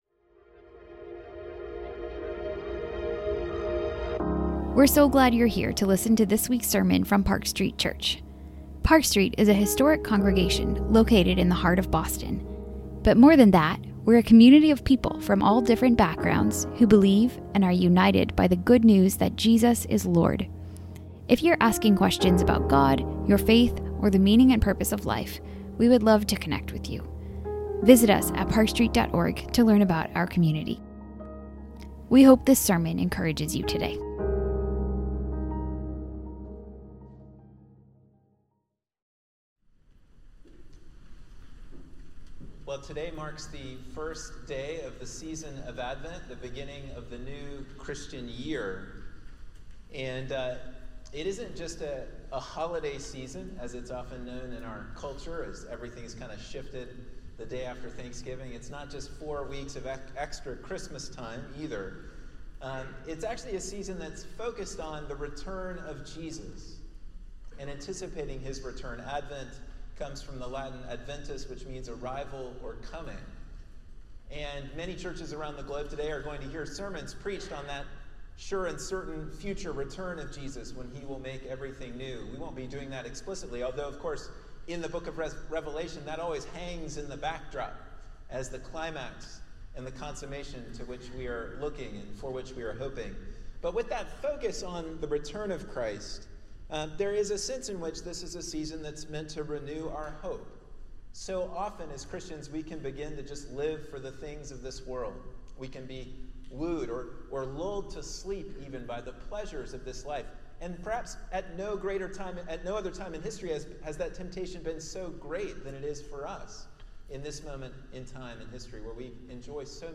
This sermon explores the vivid imagery of the seven trumpets as symbols of divine judgment. It draws insights about God's judgment, rescue, sovereignty, responsiveness, and restraint as a means of encouraging Christian disciples and inviting all to respond to God's mercy.…